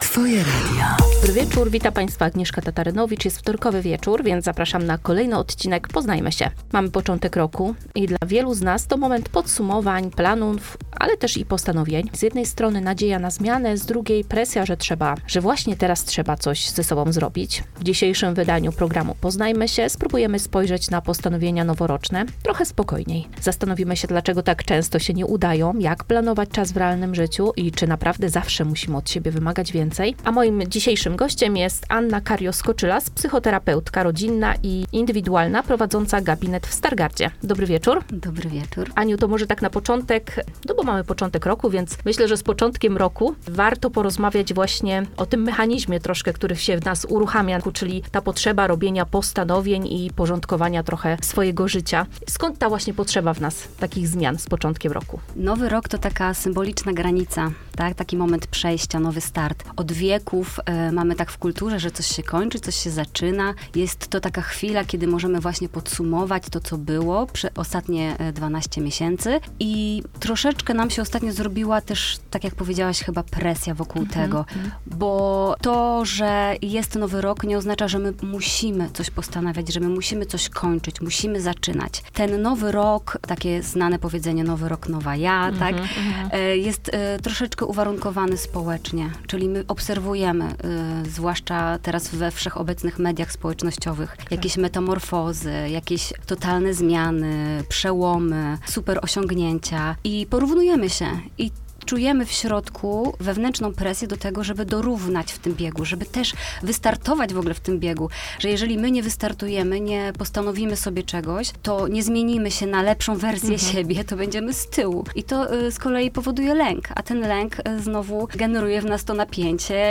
To rozmowa o małych krokach zamiast wielkich deklaracji, o odpuszczaniu bez poczucia winy i o tym, że nie zawsze musimy od siebie wymagać więcej.